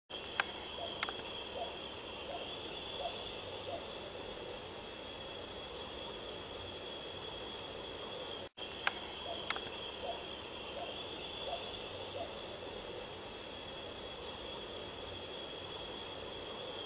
Lechuza Estriada (Strix virgata)
Nombre en inglés: Mottled Owl
País: Colombia
Provincia / Departamento: Tolima
Condición: Silvestre
Certeza: Vocalización Grabada